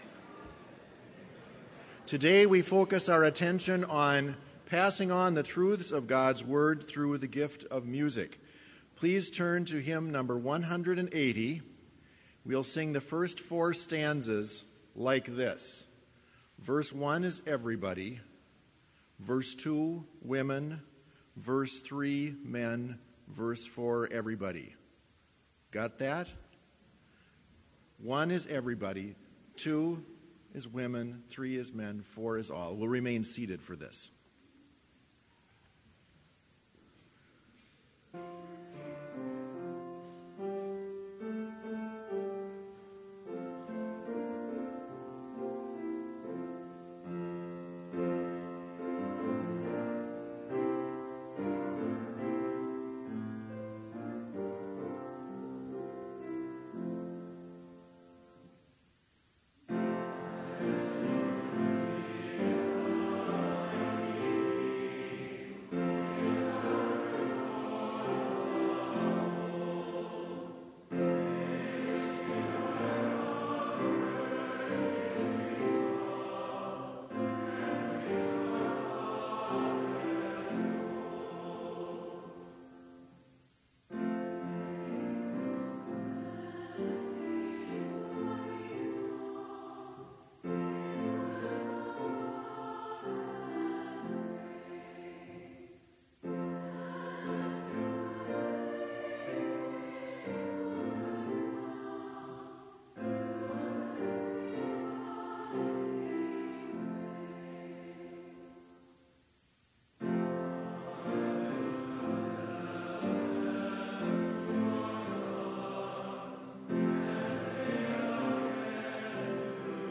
Complete service audio for Chapel - January 17, 2019